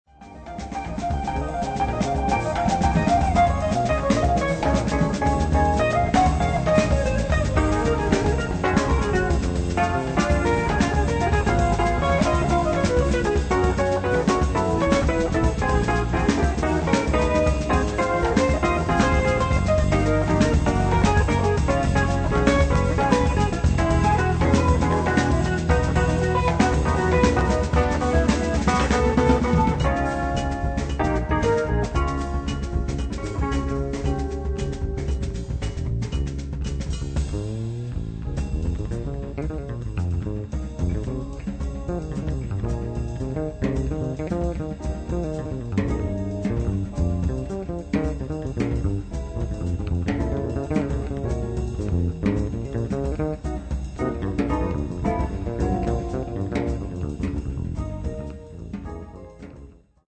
in eight funk and latin originals.
Guitar
Fretless Electric Bass
Drums